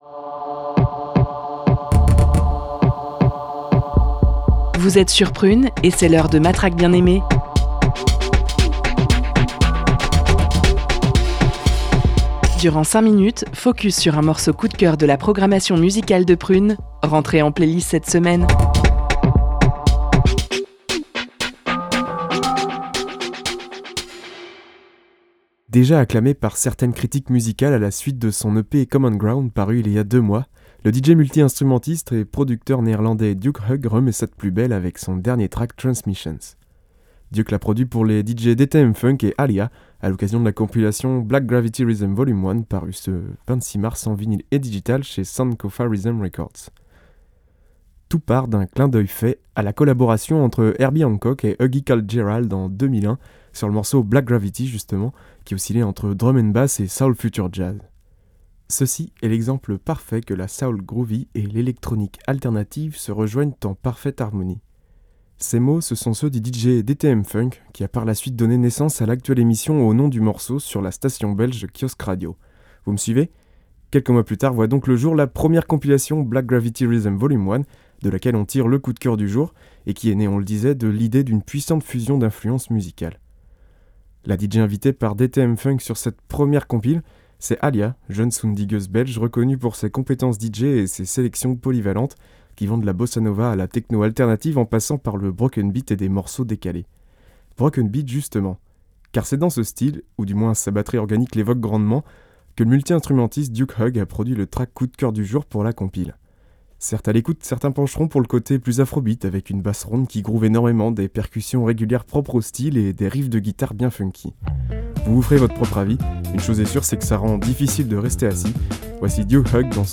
le DJ multi-instrumentiste et producteur néerlandais